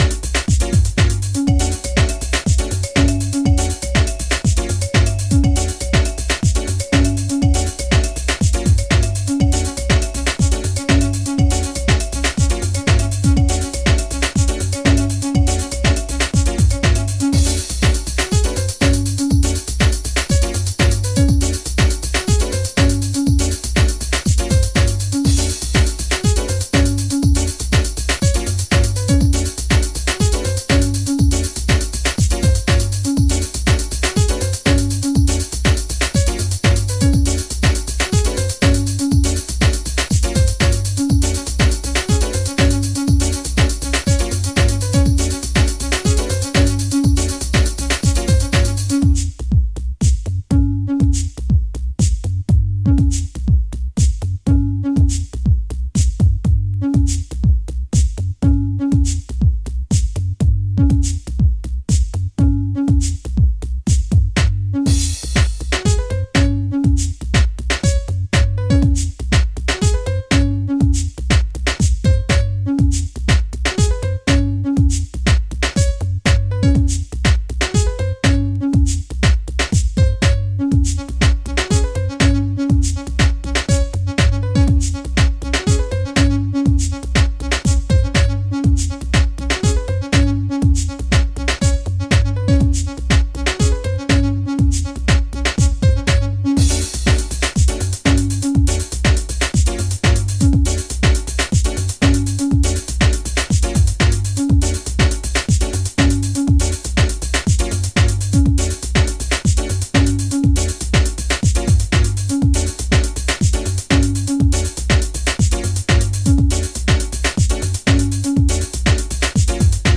deep, soulful, jazz infected Garage sound